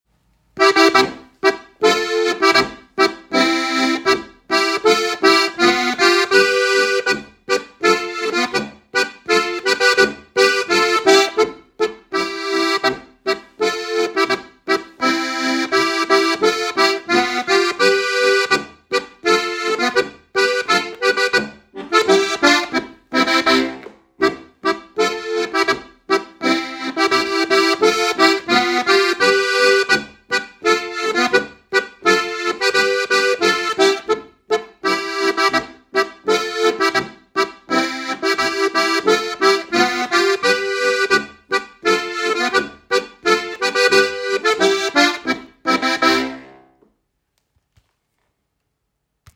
• 3-reihige Harmonika
• 4-reihige Harmonika